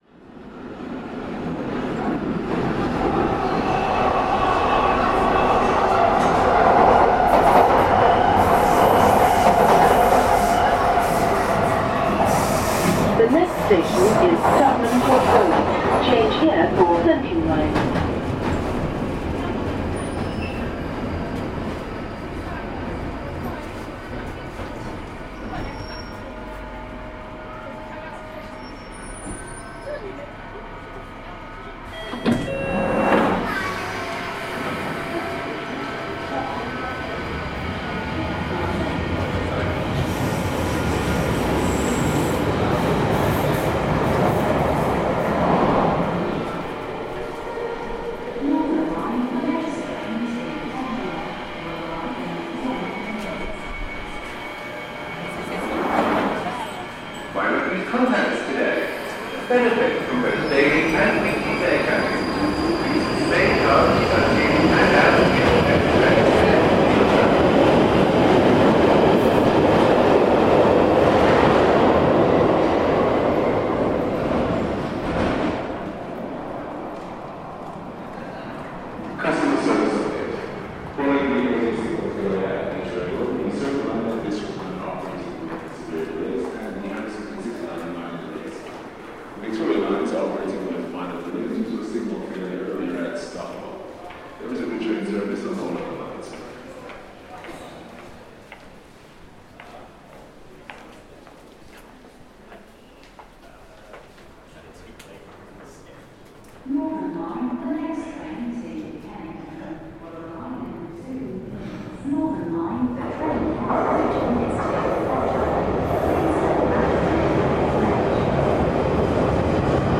Tottenham Court Road station - multiple train arrivals
Field recording from the London Underground by Cities and Memory.